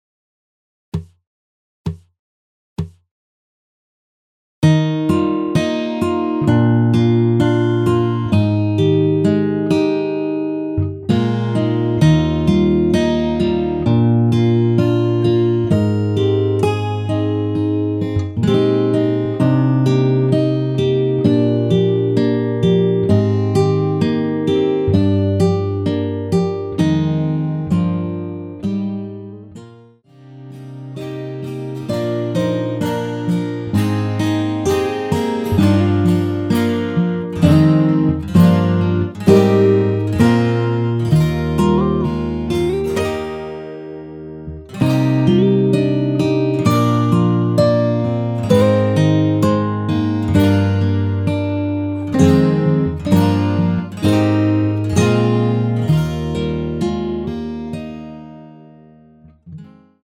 전주없이 시작 하는 곡이라 카운트 넣어 놓았습니다.(미리듣기 참조)
원키(1절+후렴)으로 진행되는 MR입니다.
Eb
앞부분30초, 뒷부분30초씩 편집해서 올려 드리고 있습니다.
중간에 음이 끈어지고 다시 나오는 이유는